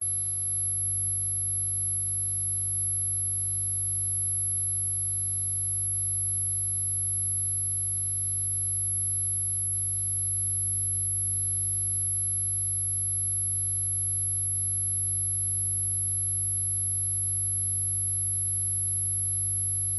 Звуки зарядного устройства
Звук прохождения тока через зарядное устройство (гудение)